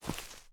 sfx_on_grass.mp3